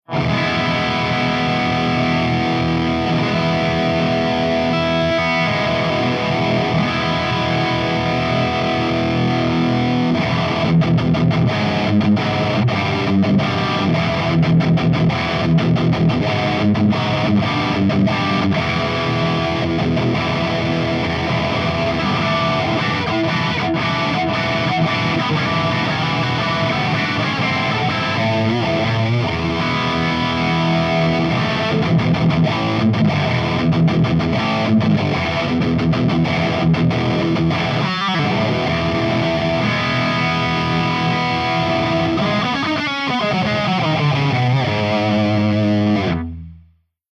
167_EVH5150_CH3HIGHGAIN_V30_P90